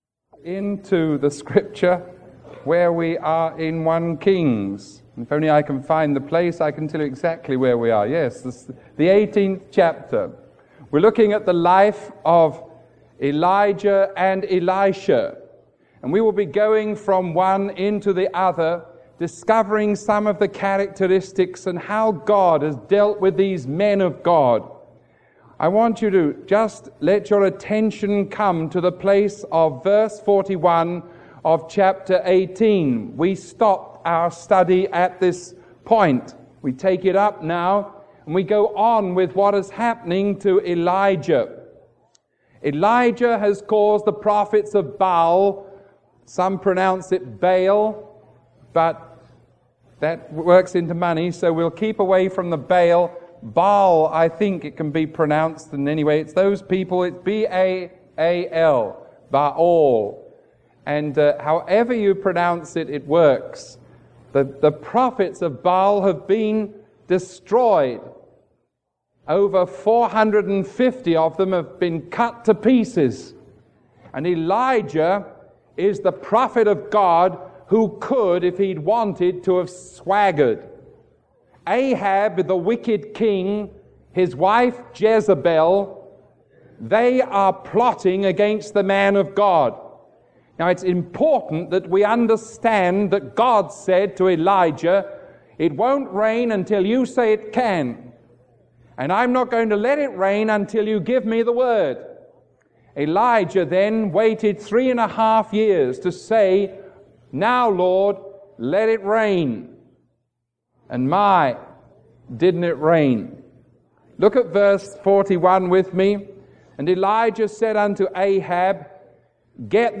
Sermon 0368A recorded on June 14